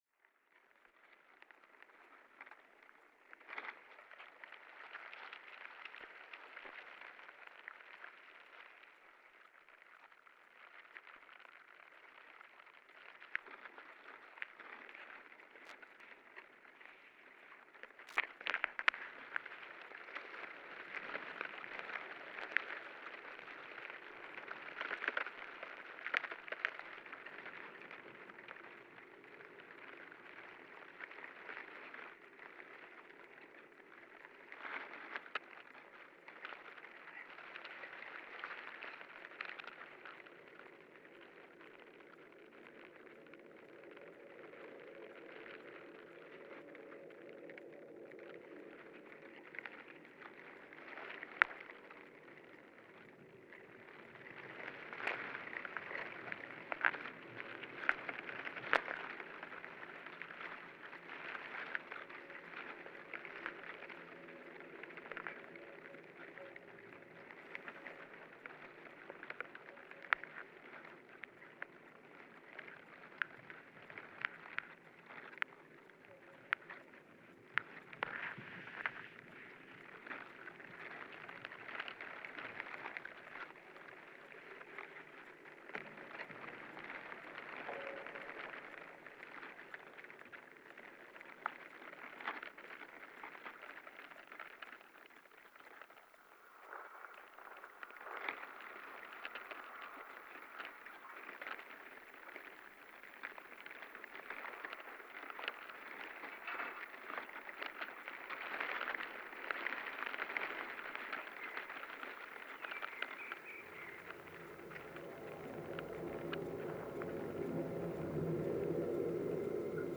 The sound collage was made only from samples recorded with contact microphones, on and around the bridge in June 2013.